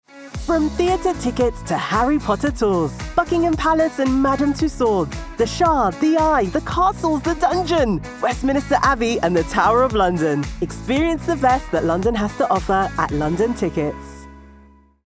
Female
Radio Commercials
Tour Tickets Promo
All our voice actors have professional broadcast quality recording studios.